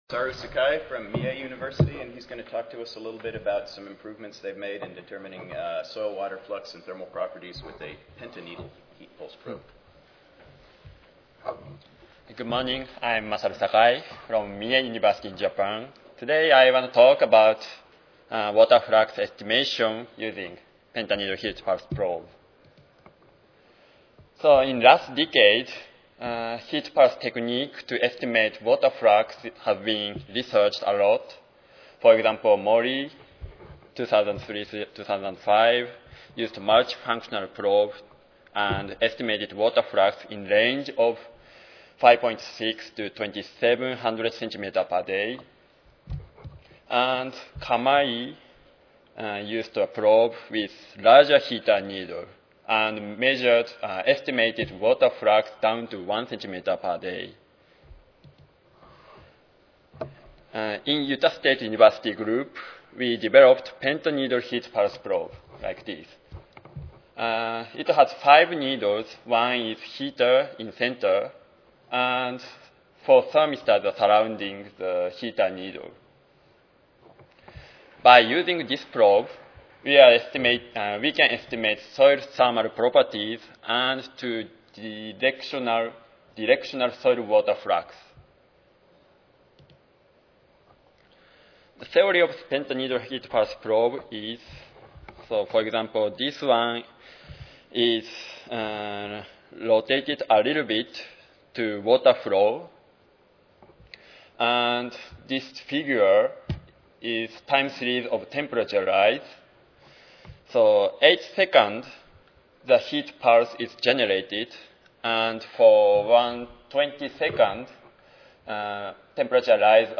S01 Soil Physics Session: Measurement and Modeling of near-Surface Soil Water and Energy Fluxes: I (ASA, CSSA and SSSA Annual Meetings (San Antonio, TX - Oct. 16-19, 2011))
Utah State University Recorded Presentation Audio File